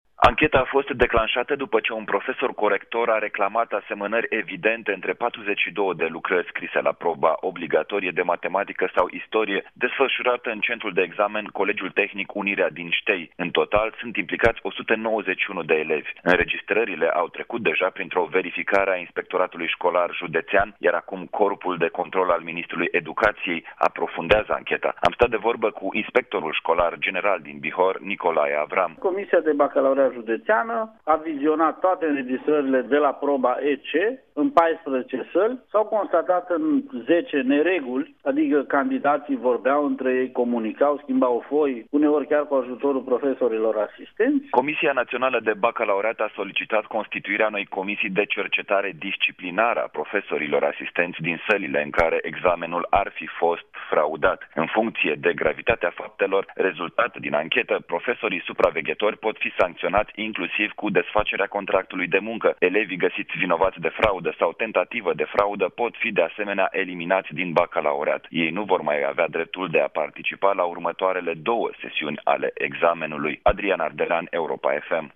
corespondent Europa FM: